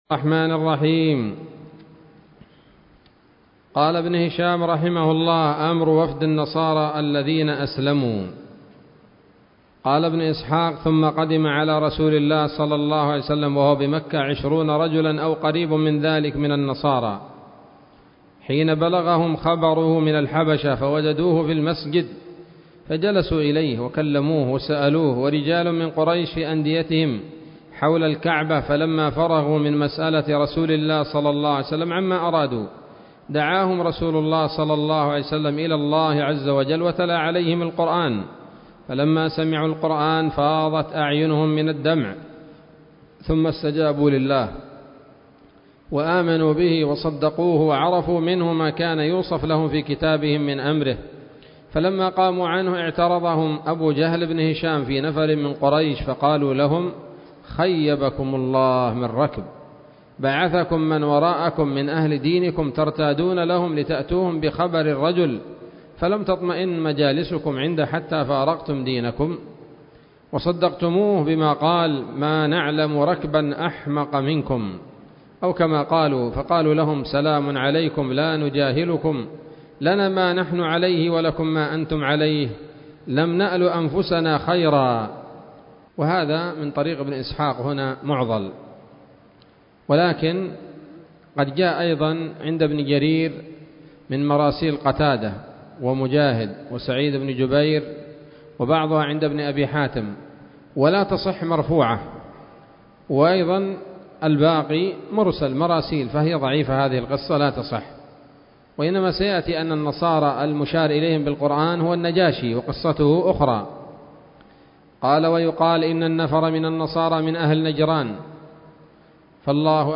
الدرس السابع والأربعون من التعليق على كتاب السيرة النبوية لابن هشام